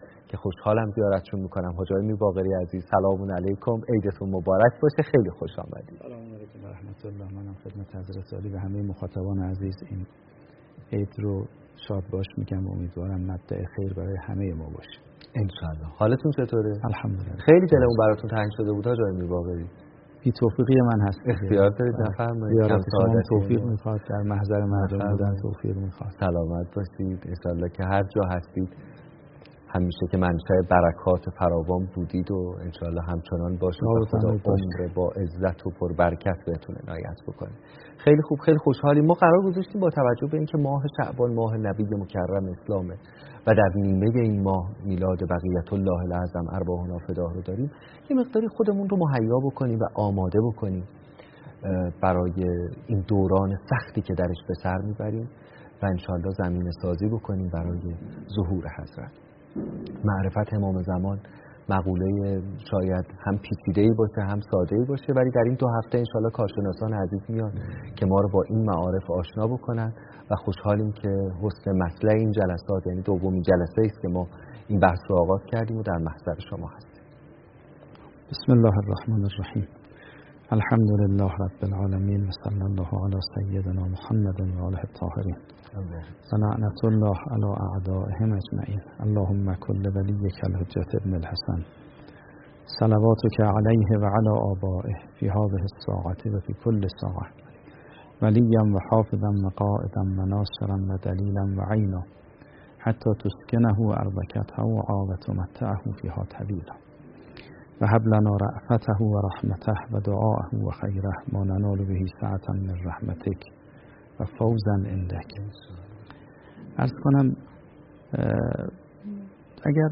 دانلود سخنرانی مفید و کاربردی آیت الله میرباقری در مورد عصر ظهور و وظایف منتظران – فروردین ۱۴۰۰